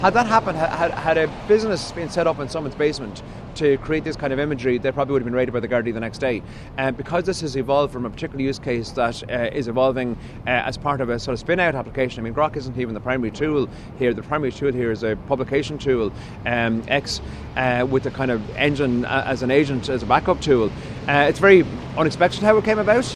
Minister James Lawless says it’s a complicated issue……………….